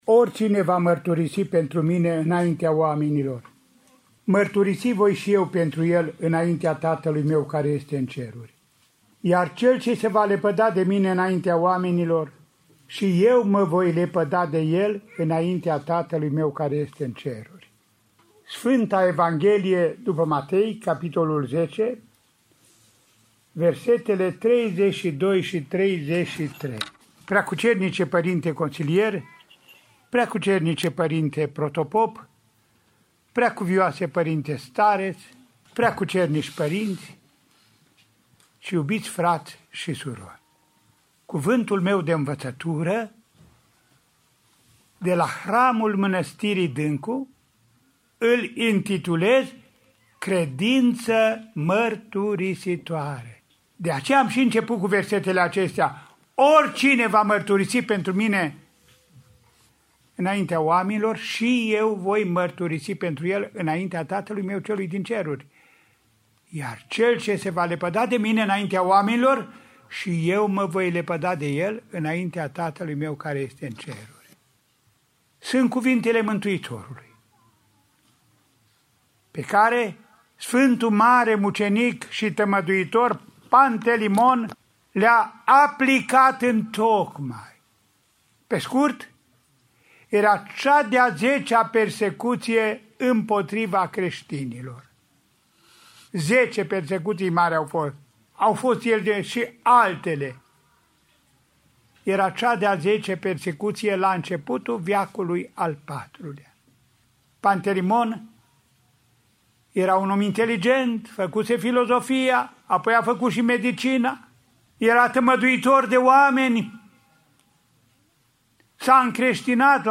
Luni, 27 iulie 2020, la sărbătoarea Sfântului Mare Mucenic și Tămăduitor Pantelimon, Înaltpreasfințitul Părinte Andrei, Arhiepiscopul Vadului, Feleacului și Clujului și Mitropolitul Clujului, Maramureșului și Sălajului, a liturghisit și a predicat la Mănăstirea Dâncu, județul Cluj, cu prilejul hramului.
De la ora 10:00, pe un podium special amenajat în curtea mănăstirii, Părintele Mitropolit Andrei a oficiat Sfânta Liturghie, înconjurat de un sobor de clerici.